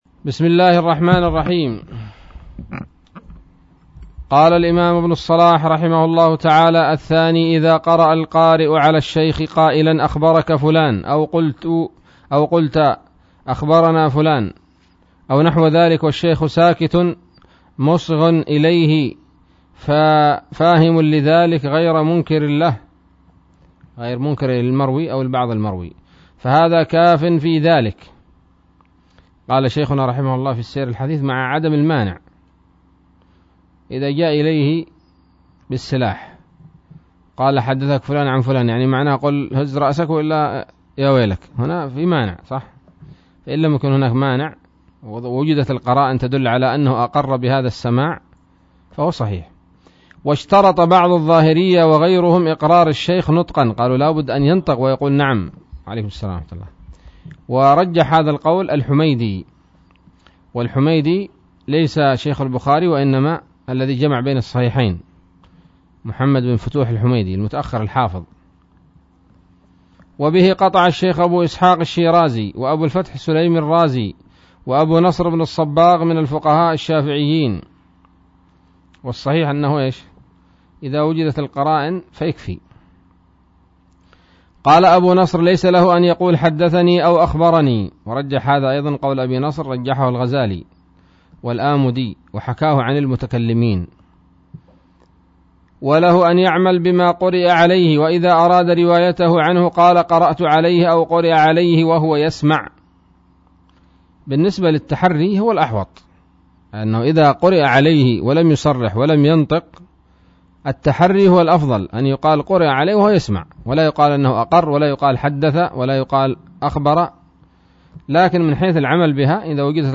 الدرس الثالث والستون من مقدمة ابن الصلاح رحمه الله تعالى